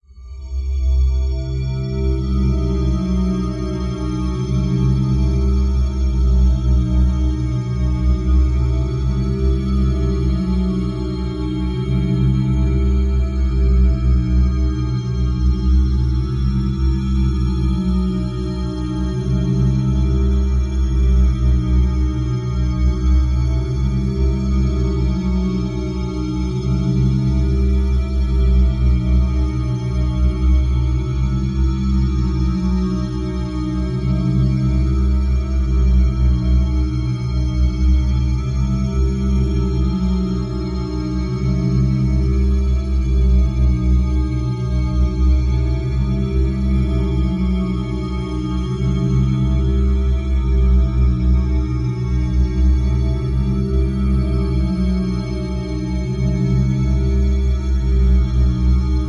Tag: 回路 环境 爬行 科学 低音